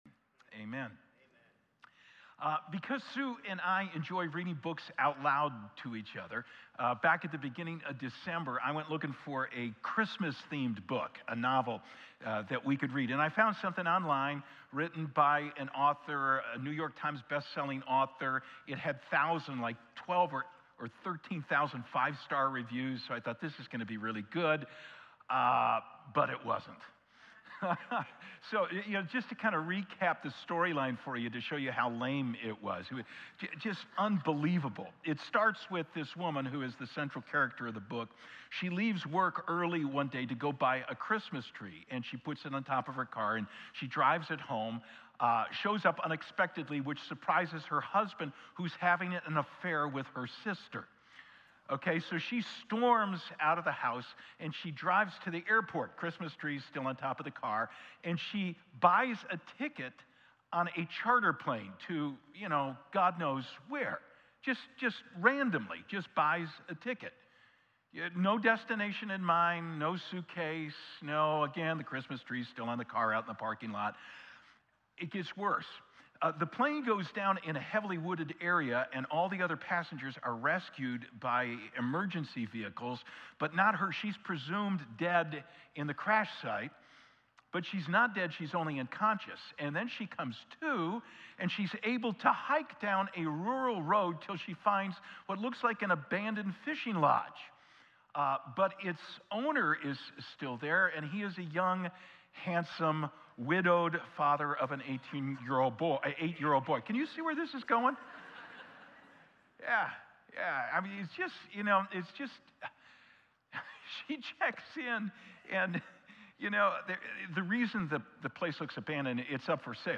1-5-25-Sermon.mp3